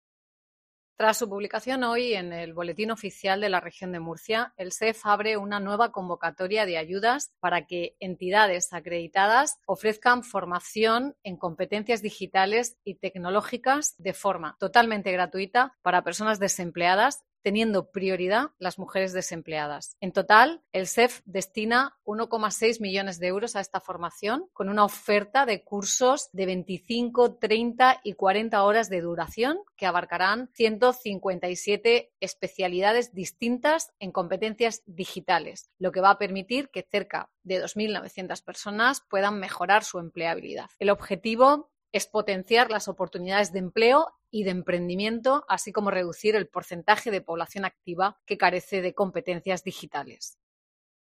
Marisa López, directora general del SEF